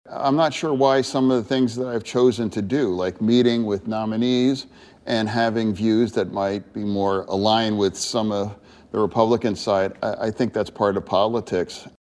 Fetterman Speaks on Trump Appointees
PENNSYLVANIA DEMOCRAT SENATOR JOHN FETTERMAN HAS MET WITH SOME OF THE PRESIDENT-ELECT’S TOP PICKS. ON SUNDAY, HE APPEARED ON A-B-C’S “THIS WEEK” WHERE HE SAID THAT WORKING ACROSS THE AISLE IS IMPORTANT TO HIS WORK…